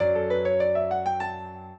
piano
minuet11-4.wav